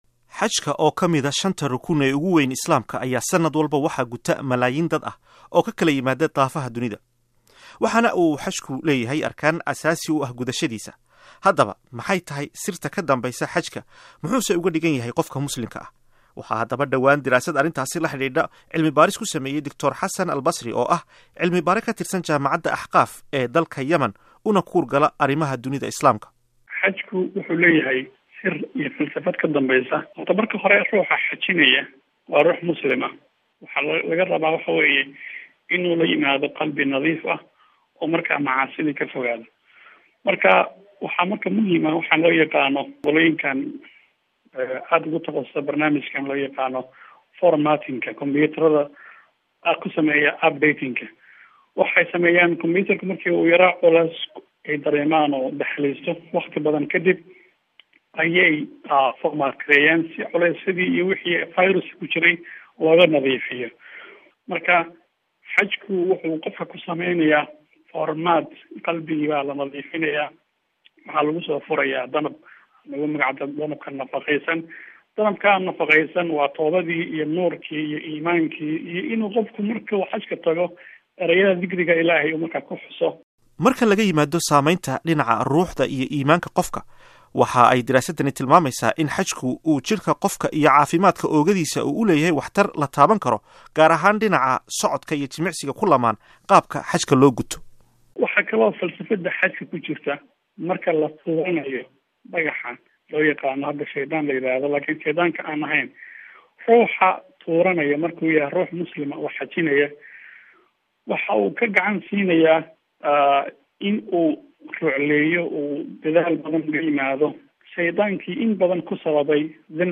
Warbixinta Xajka sanadkaan 2016